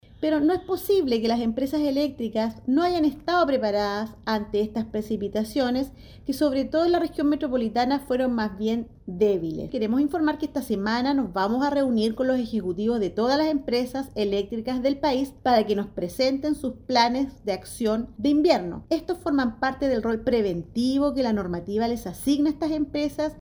La superintendenta de Electricidad y Combustibles, Marta Cabeza, señaló que el sistema frontal no solo fue previsto, sino también que tuvo un carácter “débil” en la capital, por lo que dichos antecedentes serán incorporados a la investigación que lleva a cabo la autoridad.